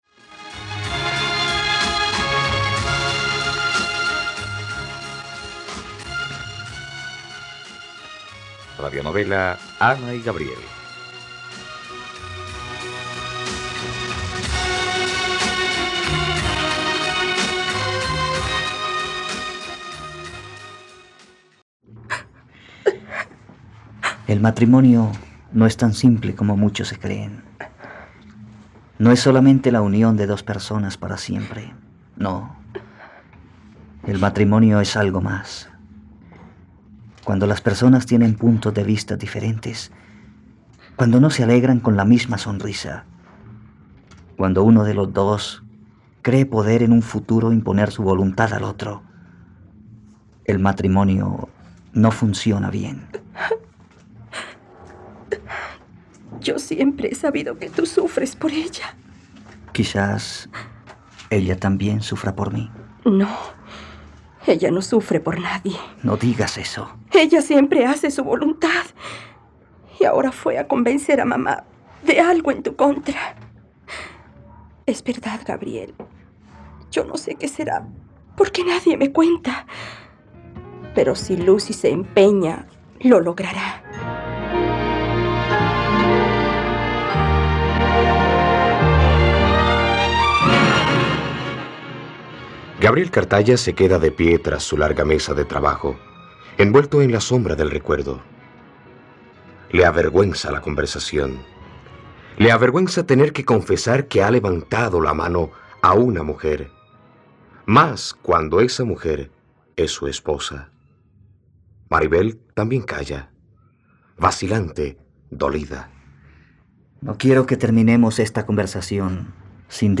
..Radionovela. Escucha ahora el capítulo 21 de la historia de amor de Ana y Gabriel en la plataforma de streaming de los colombianos: RTVCPlay.